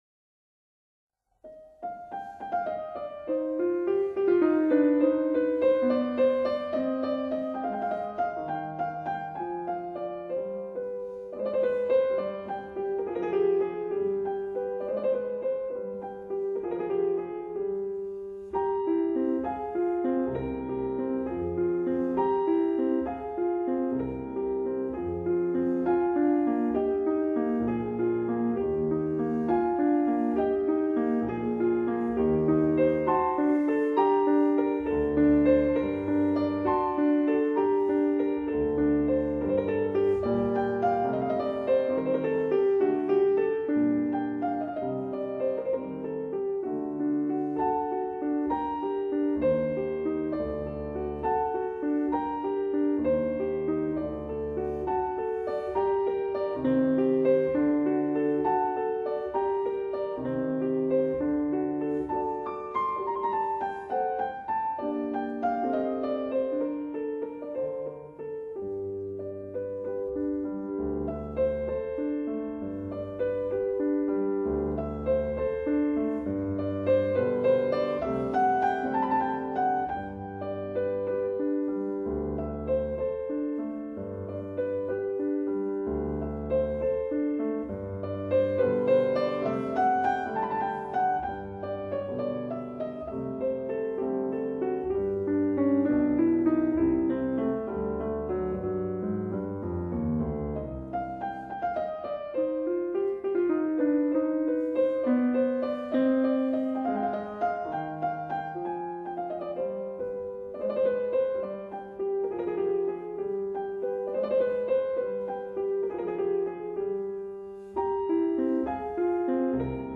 ●以鋼琴彈奏充滿詩意。